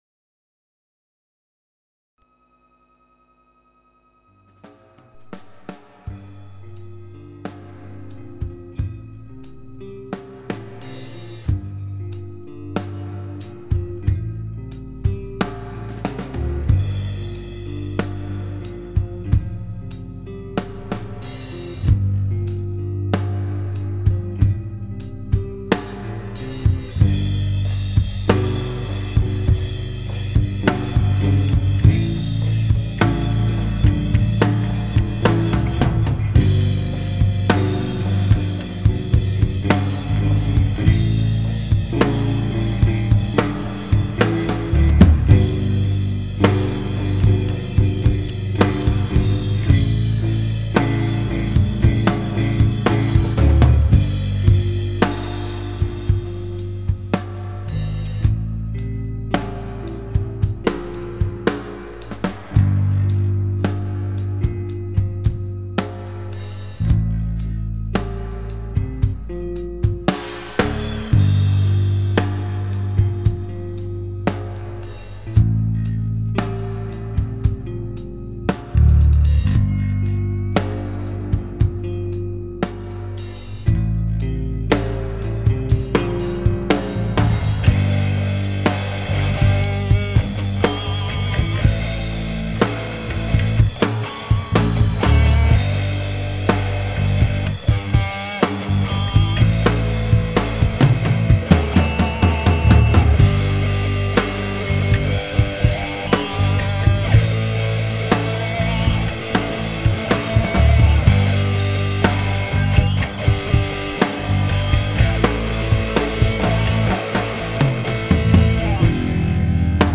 Voil�, enregistr� avec les pr�ampli de la Studiomaster proline 32... direct dans la carte son et mix sous cubase sx.